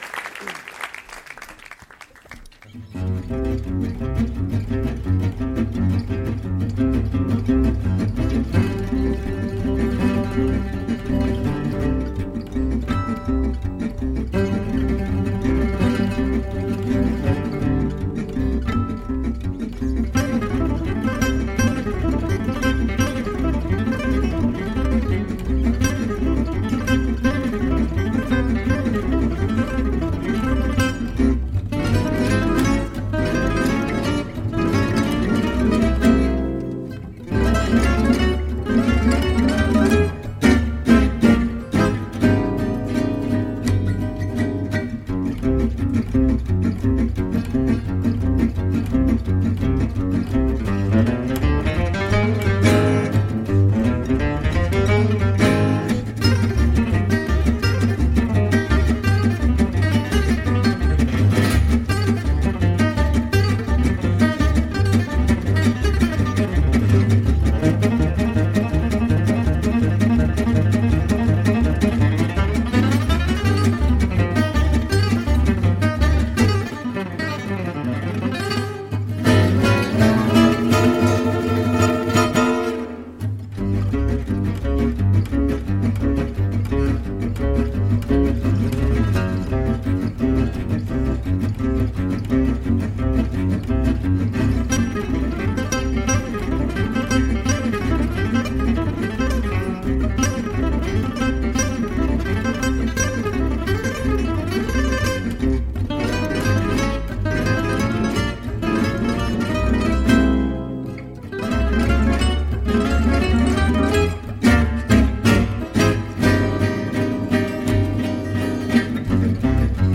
perpétuent la musique de Django.
violon
guitare solo
contrebasse